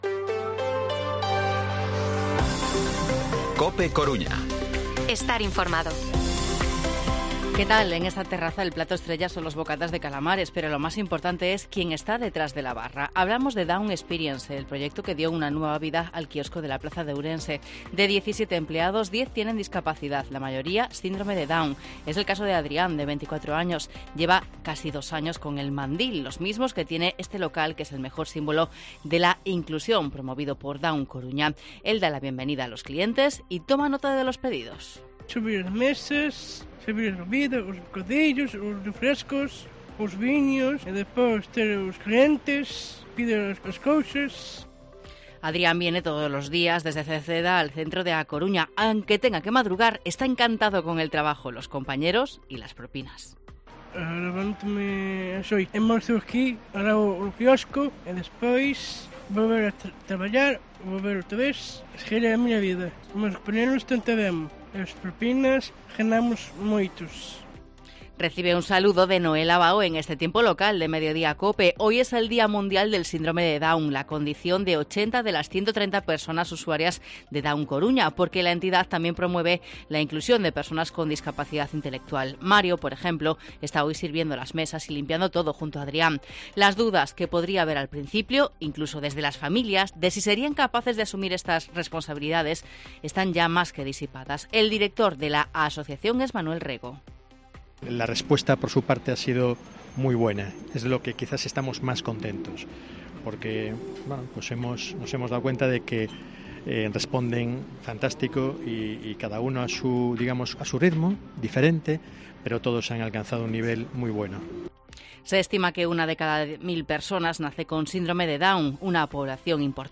Informativo Mediodía COPE Coruña martes, 21 de marzo de 2023 14:20-14:30